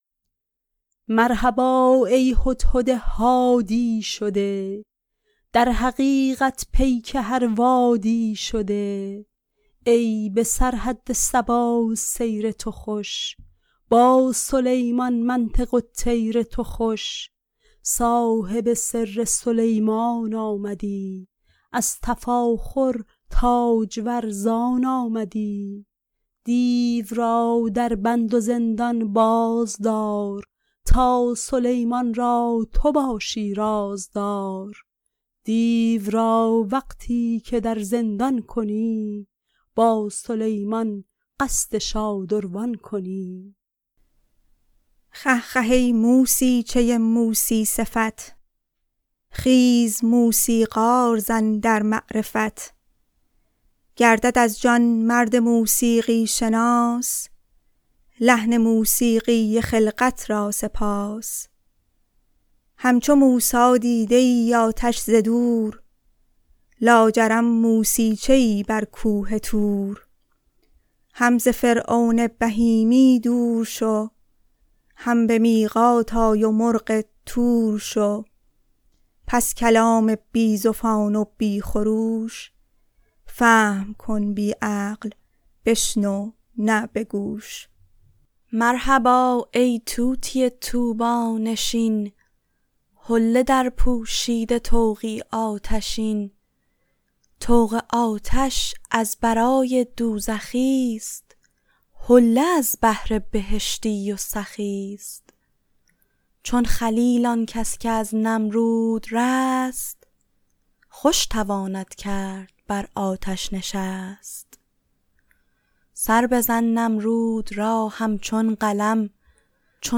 به خوانش گروه چامه‌خوان